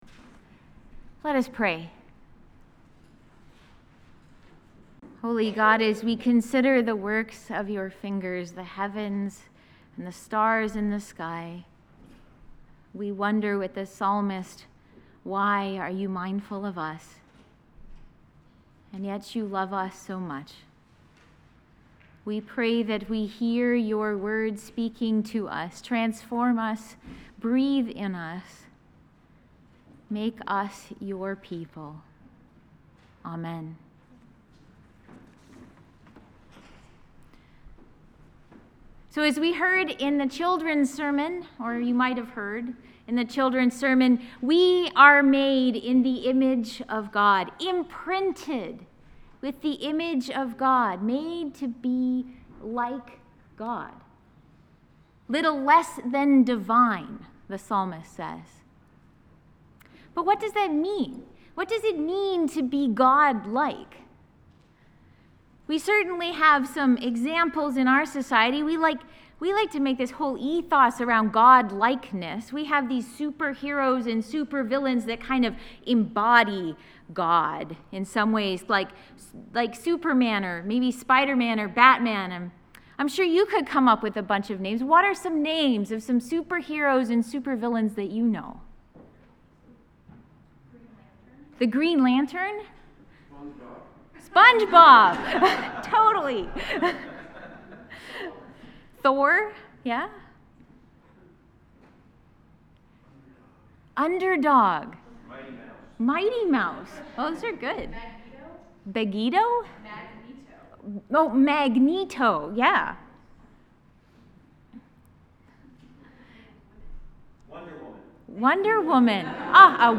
Audio of sermon: